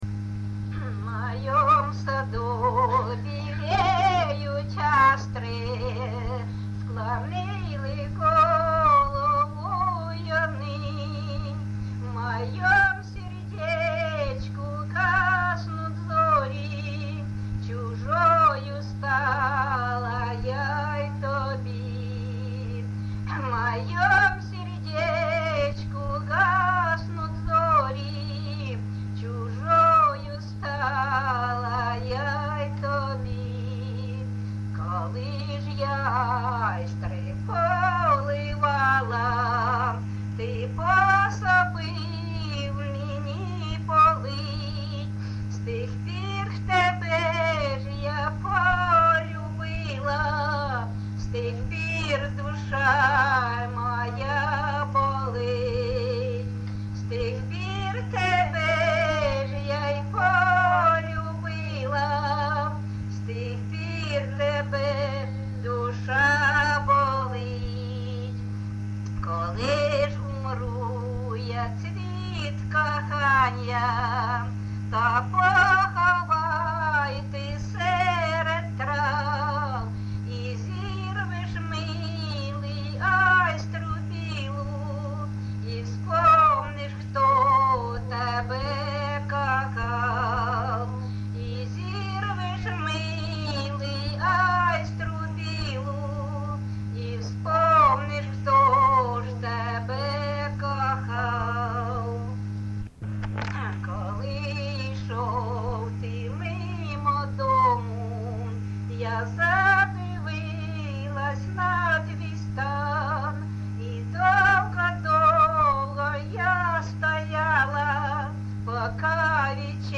ЖанрРоманси, Сучасні пісні та новотвори
Місце записус. Бузова Пасківка, Полтавський район, Полтавська обл., Україна, Полтавщина